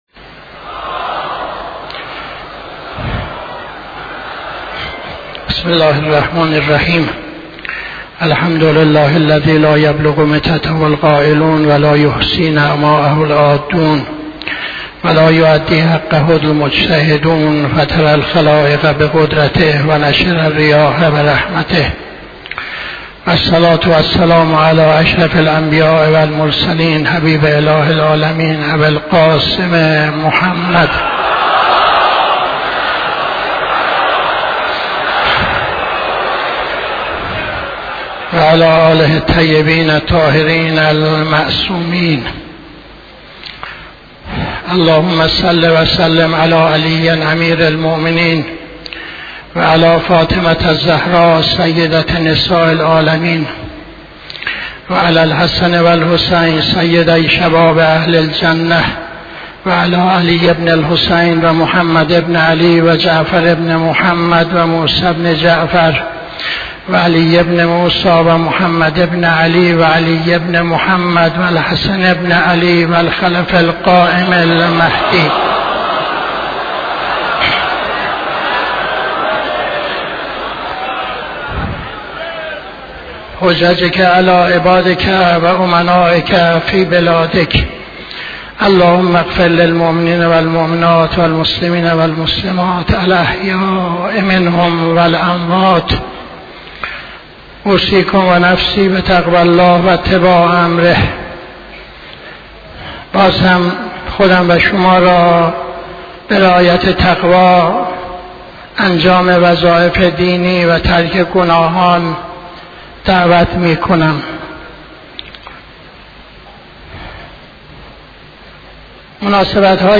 خطبه دوم نماز جمعه 13-10-81